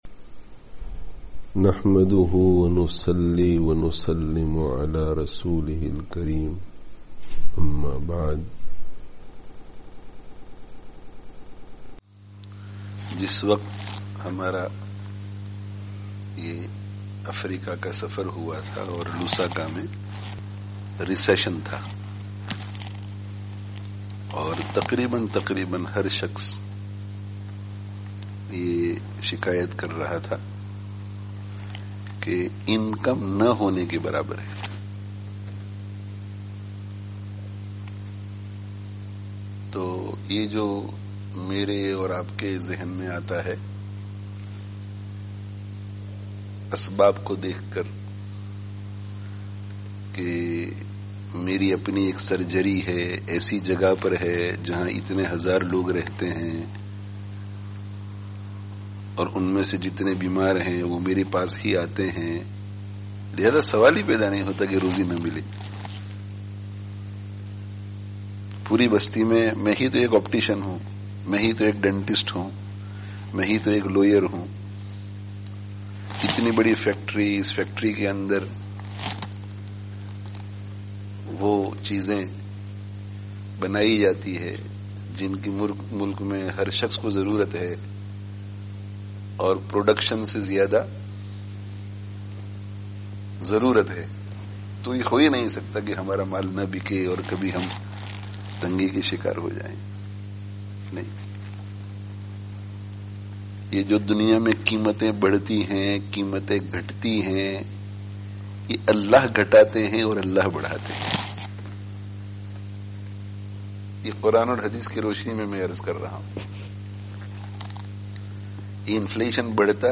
Download friday tazkiyah gathering Urdu 2020 Related articles Allāh ta'ālā kī Farmā(n)bardārī me(n) Kāmyābī hī Kāmyābī hai (14/08/20) Be Shumār Ni'mato(n) ke Bāwajūd Mahrūmī kā Ihsās?